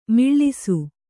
♪ miḷḷisu